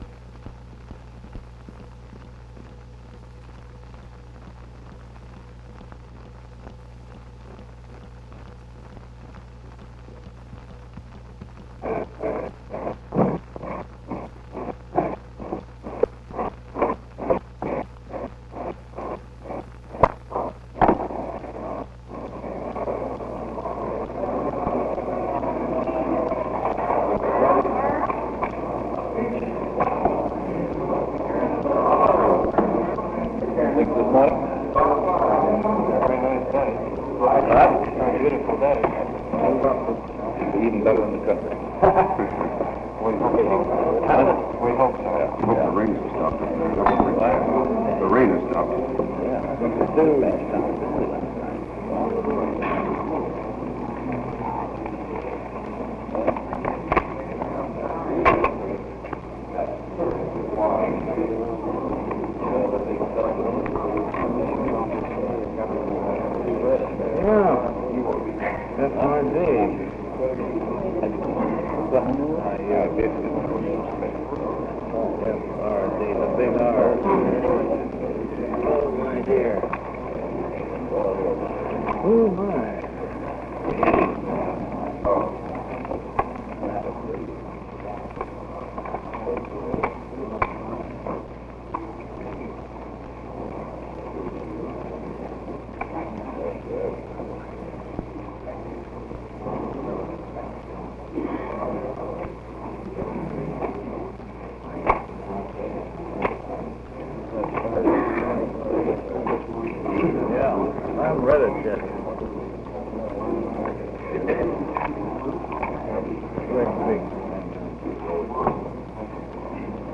Press Conference 678
Secret White House Tapes | Franklin D. Roosevelt Presidency